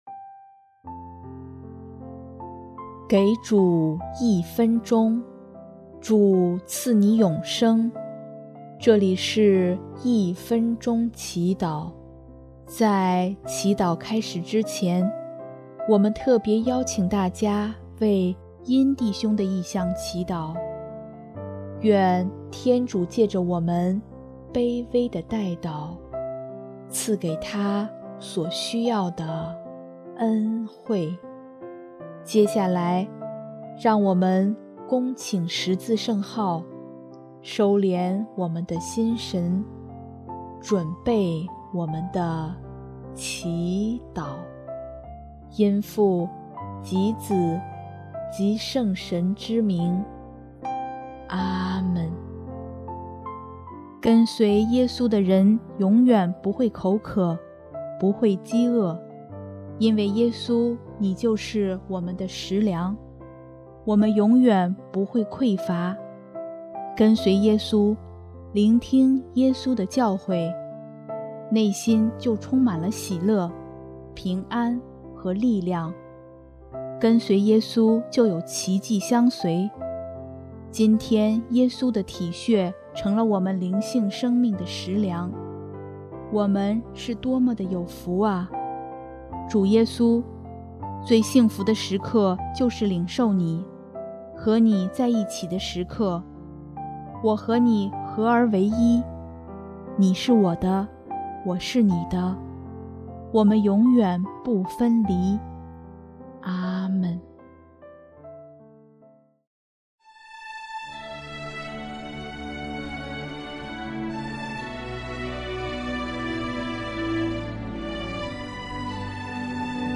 【一分钟祈祷】|6月22日 耶稣是我们的食粮
音乐： 第一届华语圣歌大赛获奖歌曲